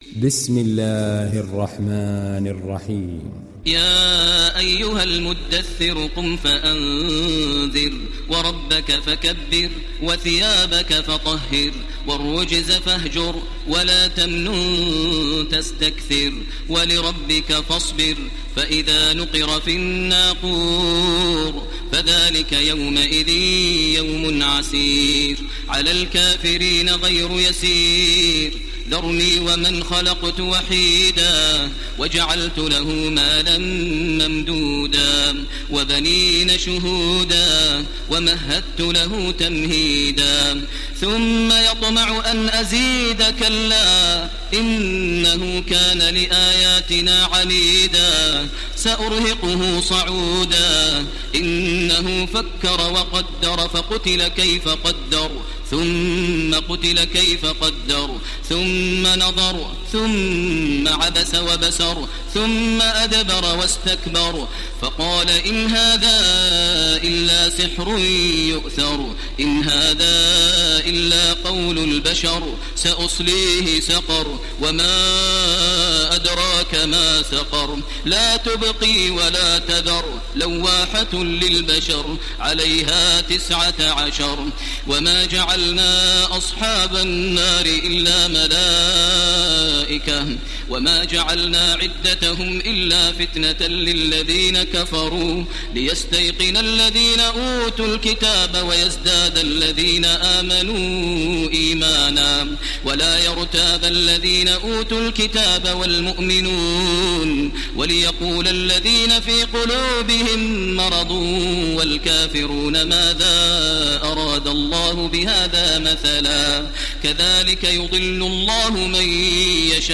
دانلود سوره المدثر تراويح الحرم المكي 1430